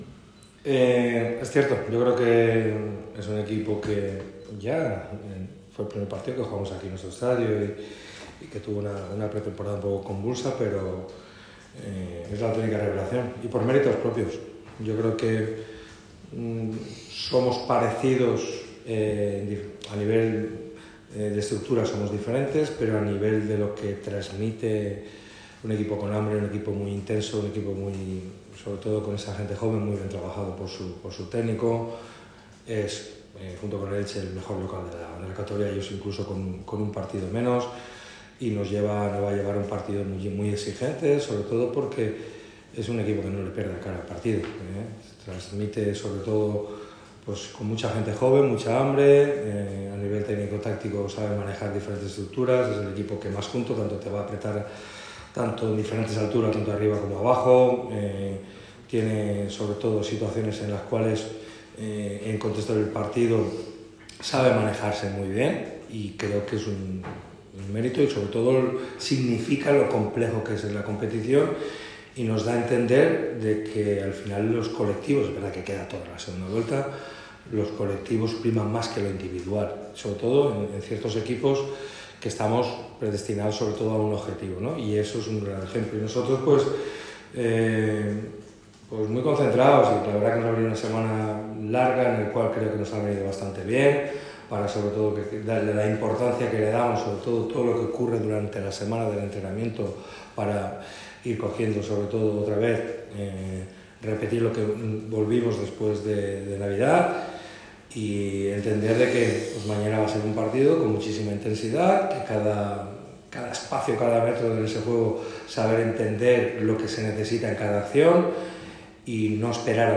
El entrenador del Málaga CF, Sergio Pellicer, ha comparecido en la sala de prensa ‘Juan Cortés’ del estadio de La Rosaleda con motivo de la previa del CD Mirandés-Málaga CF de este lunes. El técnico de Nules ha dejado varios titulares destacados, haciendo un repaso al estado del equipo tras la semana de trabajo, las lesiones, el mercado de fichajes y varios nombre propios, como el de Chupete.